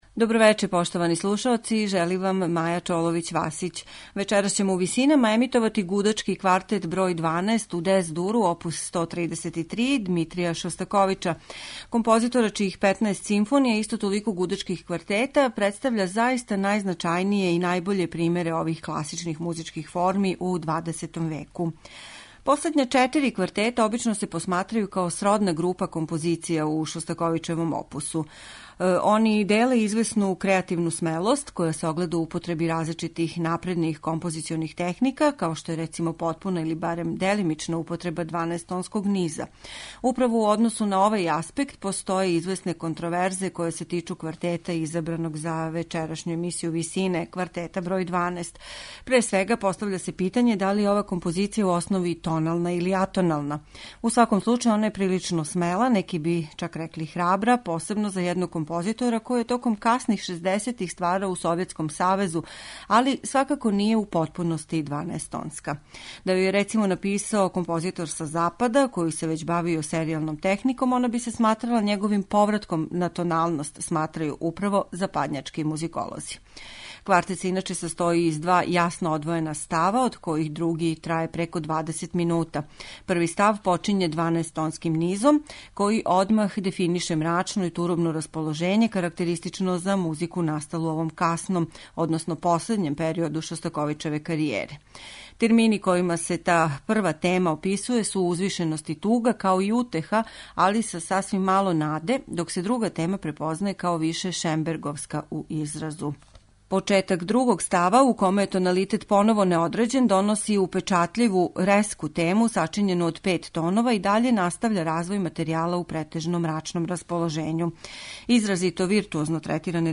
Емисија у први план истиче медитативна дела и духовне композиције аутора свих конфесија.
У данашњој емисији Висине, имаћете прилику да слушате 12-и гудачки квартет Дмитрија Шостаковича.